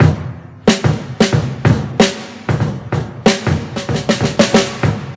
• 93 Bpm Drum Loop D# Key.wav
Free breakbeat - kick tuned to the D# note. Loudest frequency: 1445Hz
93-bpm-drum-loop-d-sharp-key-GAR.wav